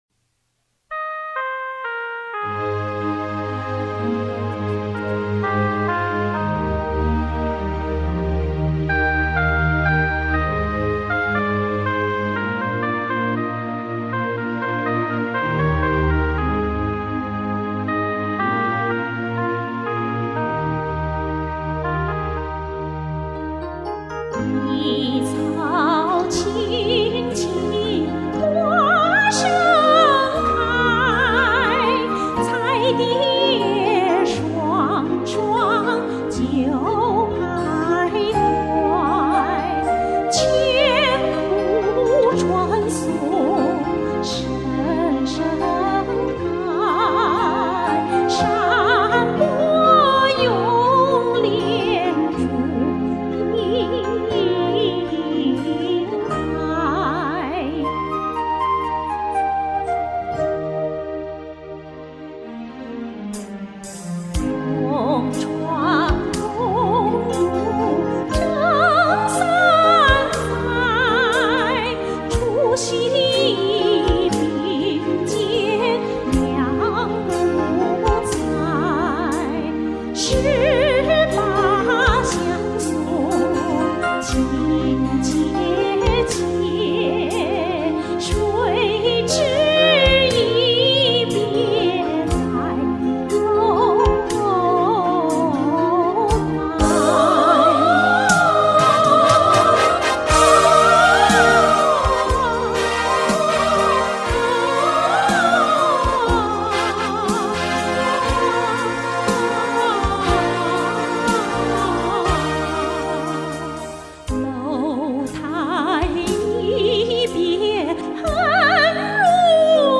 对，是自己录音制作的，很简单。